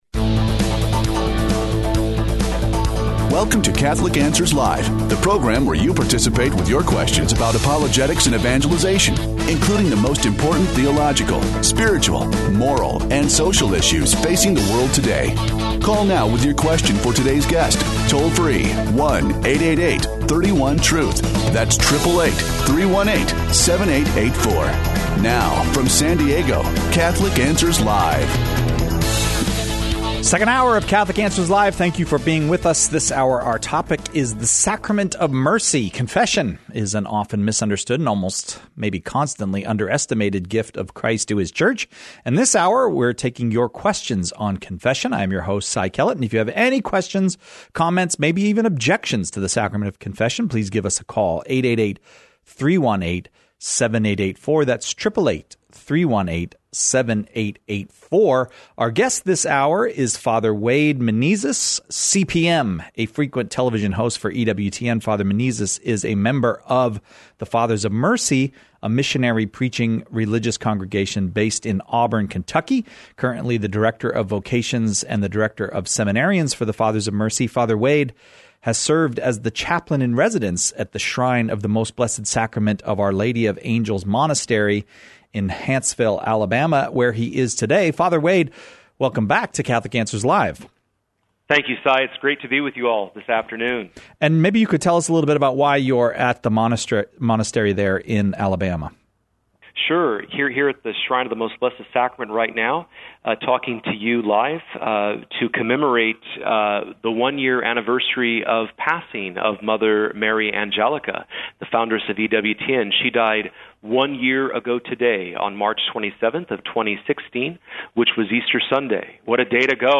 He addresses questions about how to examine one’s own conscience, how to help others to confession, and the effects of confession in the penitent’s life. Questions Covered: